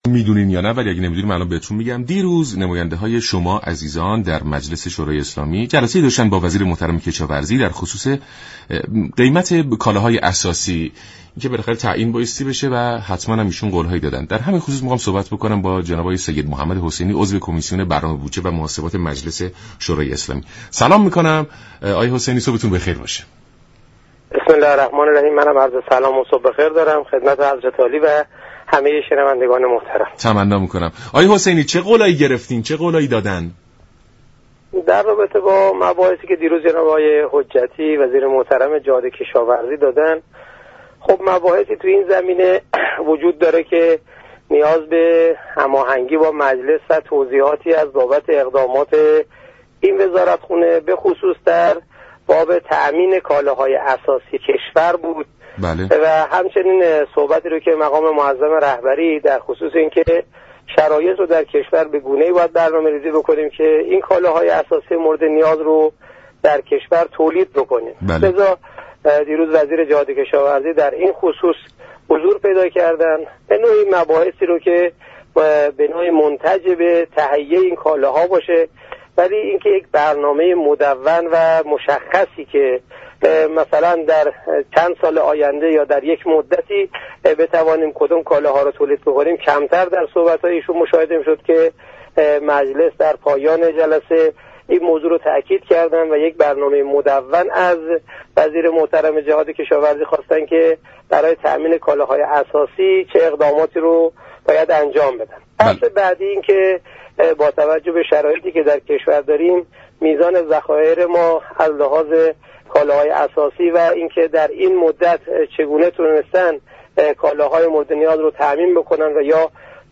به گزارش شبكه رادیویی ایران، عضو كمیسیون برنامه و بودجه و محاسبات مجلس شورای اسلامی در گفت و گو با برنامه «سلام صبح بخیر» رادیو ایران به جلسه اخیر مجلس و دولت پرداخت و گفت: روز گذشته مجلس شورای اسلامی پیرو دستور مقام معظم رهبری مبنی بر توزیع كالاهای اساسی با قیمت مناسب میان مردم، جلسه ای را با وزیر جهاد كشاورزی برگزاركرد.